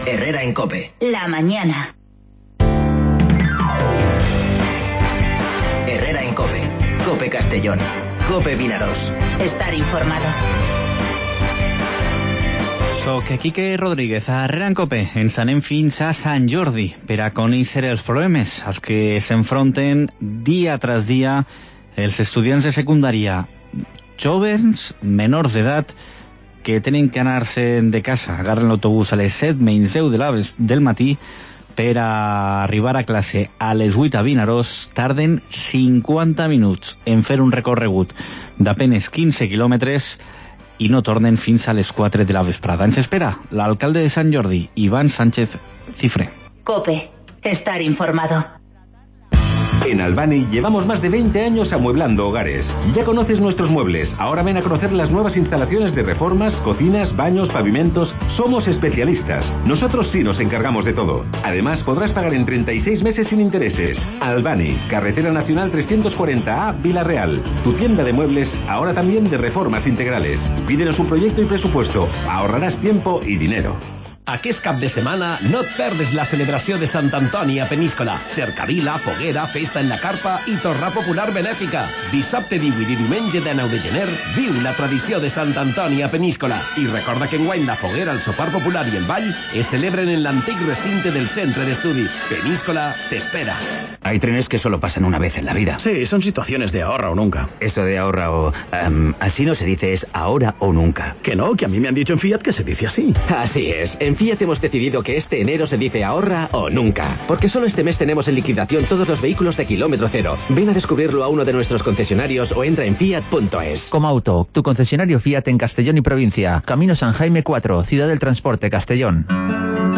AUDIO: En Herrera en COPE el alcalde de Sant Jordi, Iván Sánchez, denuncia los problemas que sufren los estudiantes de Secundaria para trasladarse...